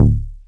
cch_bass_one_shot_eighty_D.wav